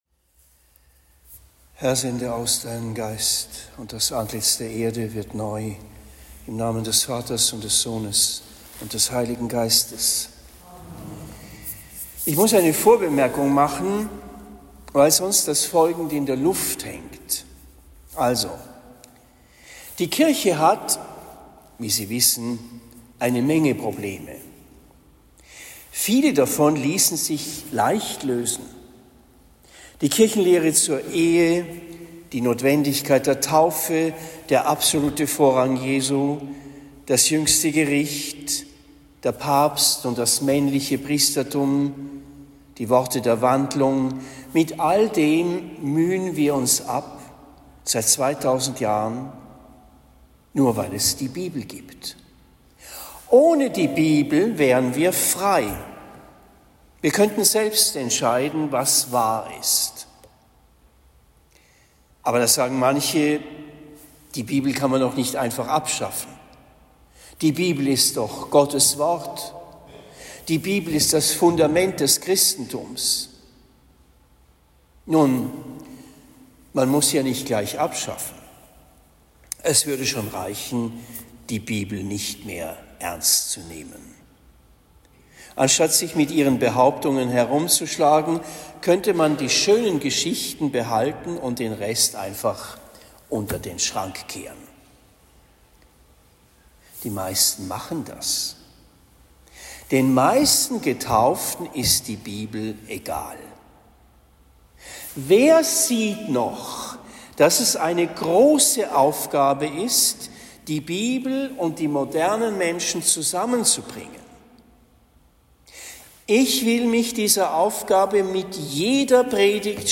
Hochfest Christkönig – Sonntag, 26. November 2023 Predigt am 26. November 2023 in St. Georg, Trennfeld am Main